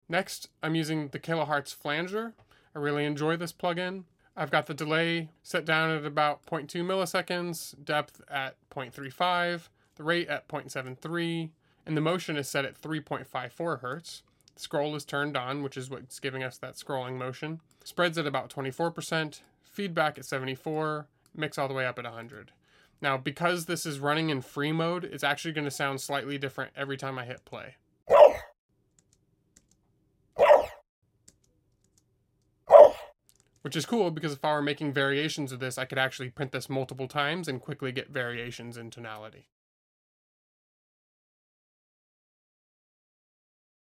⚡ A clip from my latest THIS into THAT video where I show how a flanger can give a nice sci-fi flavor to a dog bark as part of the longer process of turning it into an alien laser sweetener!